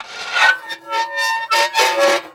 grind.ogg